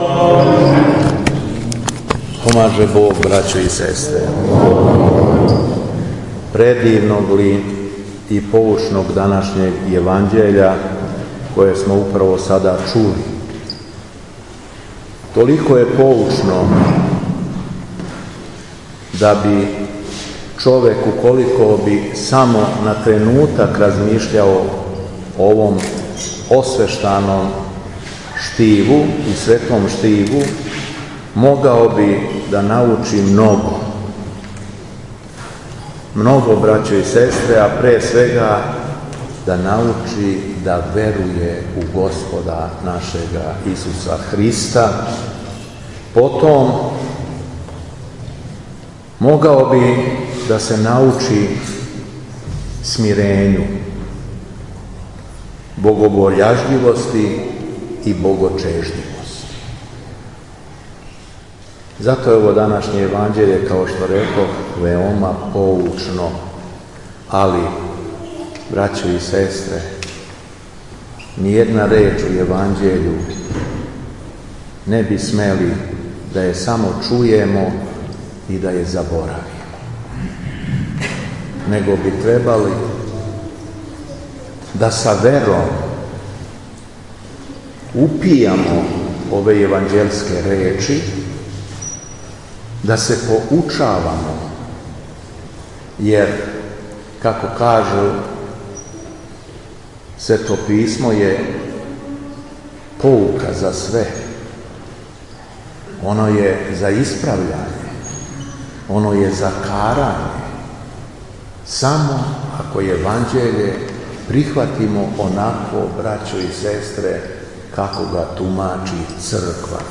АРХИЈЕРЕЈСКА ЛИТУРГИЈА У ХРАМУ СВЕТЕ ПЕТКЕ У СЕЛУ ПЕТКА НАДОМАК ЛАЗАРЕВЦА
Беседа Његовог Преосвештенства Епископа шумадијског г. Јована
У четврту недељу по Духовима, 10. јула 2022.године, када наша црква молитвено прославља преподобног Сампсона Странопримца, Његово Преосвештенство Епископ шумадијски Г. Јован служио је архијерејску Литургију у храму Свете Петке у селу Петка надомак Лазаревца.